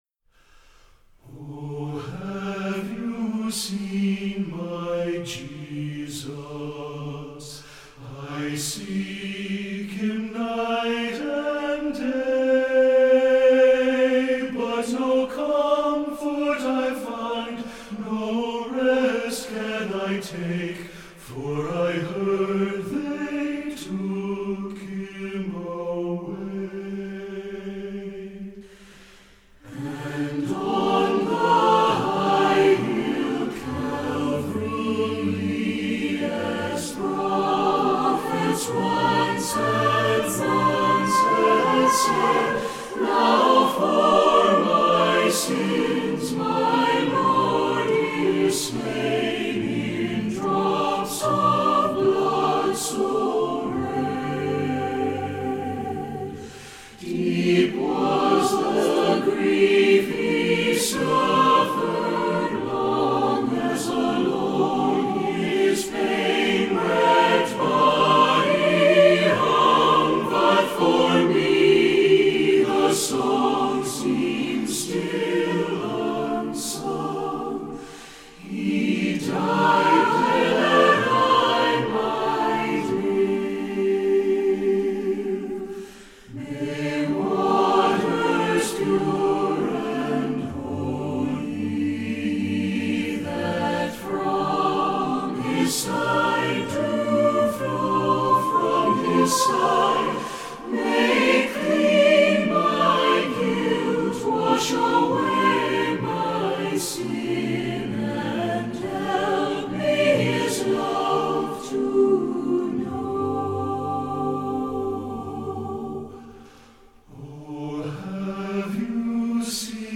Voicing: SATB a cappella